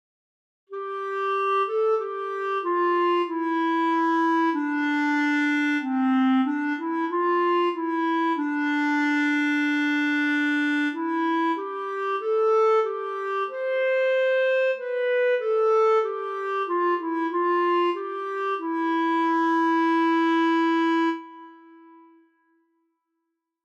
für B-Klarinette solo, Noten und Text als pdf, Audio als mp3